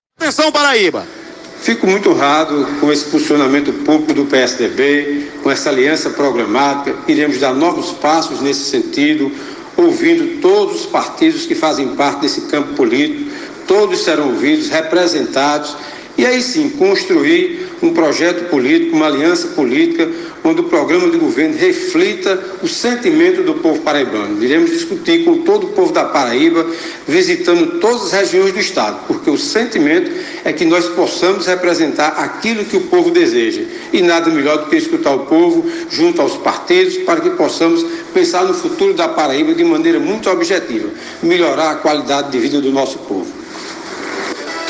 em entrevista à rádio 98 FM de João Pessoa